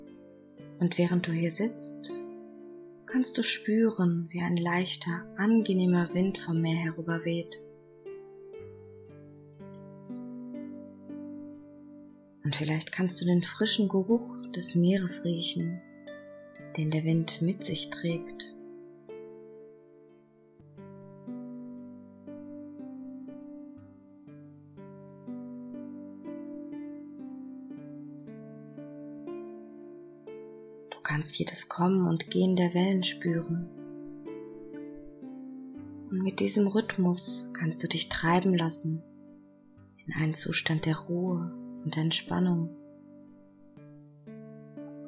Entspannung mit Hypnose – Reise ans Meer
Sprecherin